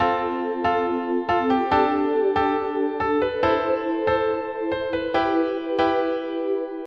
大钢琴和垫子
描述：带垫子的三角钢琴 140 bpm
Tag: 140 bpm Dance Loops Piano Loops 1.16 MB wav Key : Unknown